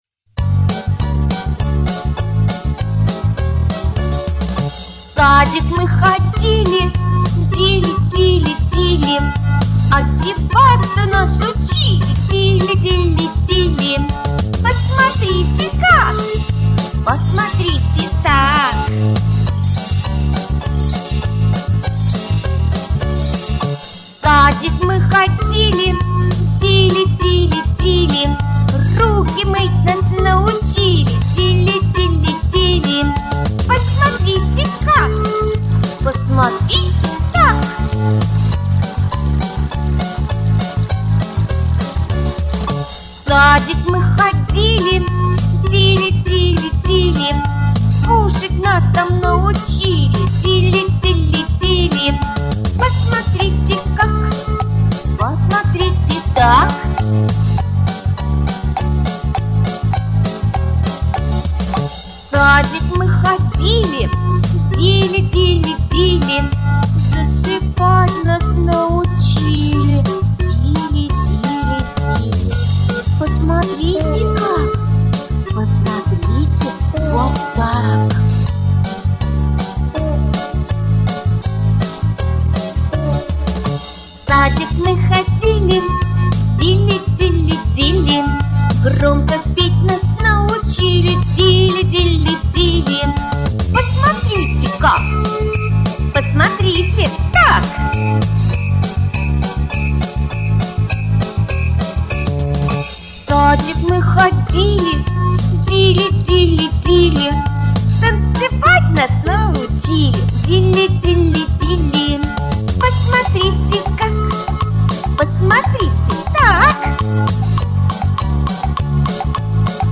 • Категория: Детские песни
теги: выпускной в детском саду, минус